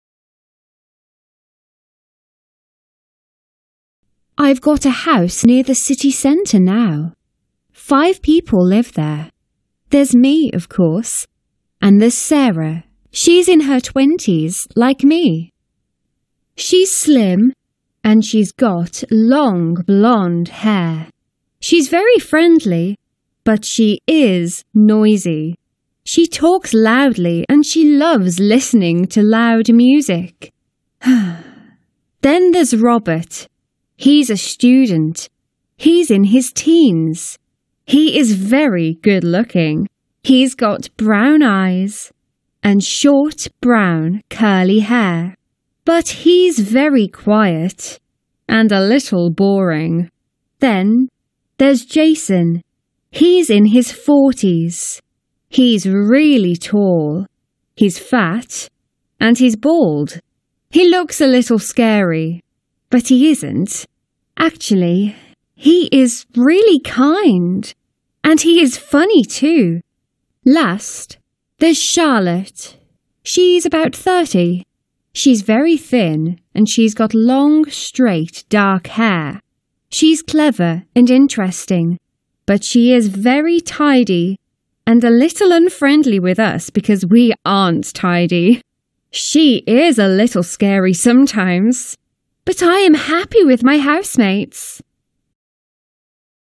It is a Listening Comprehension Task.
Audio extraído de un vídeo de text-english
Listening_Comprehension.mp3